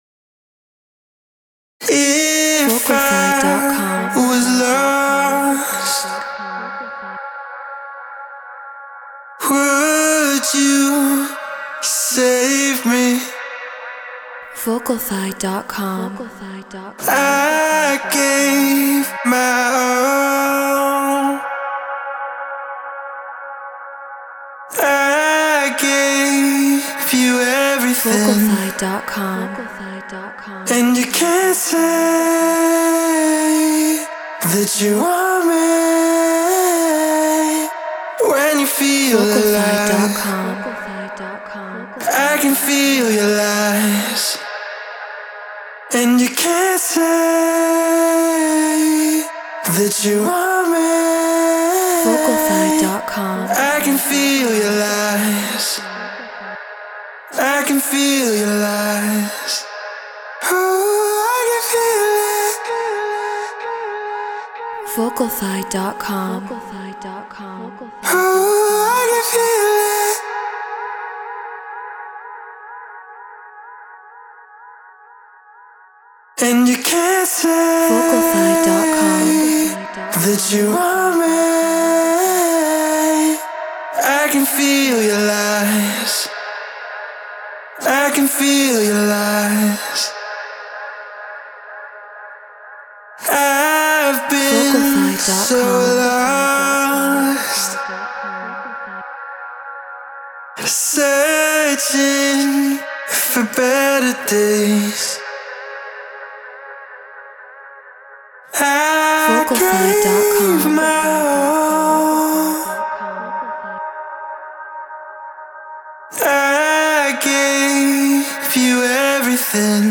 Melodic Techno 126 BPM G#min
Human-Made
RØDE NT1 Focusrite Scarlett Solo FL Studio Treated Room